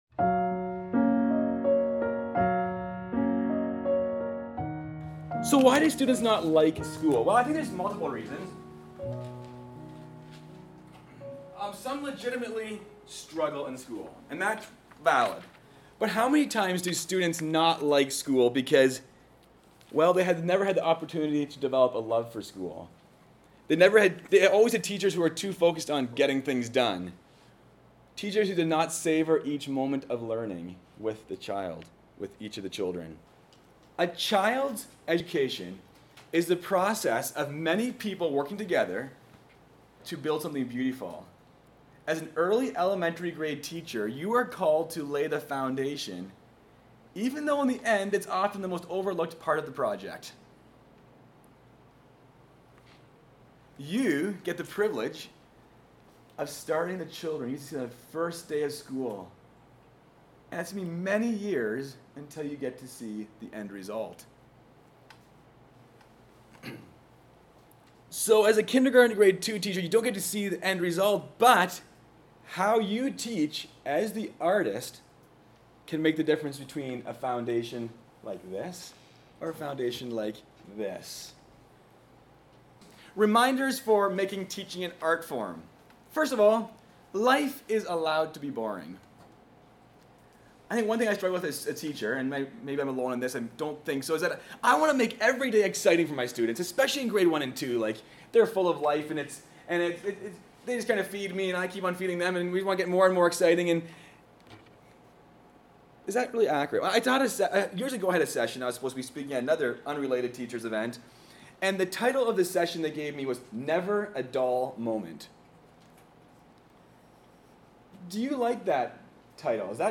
2024 Teachers Week 2023 Making Teaching an Art Form Video 00:00